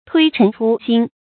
注音：ㄊㄨㄟ ㄔㄣˊ ㄔㄨ ㄒㄧㄣ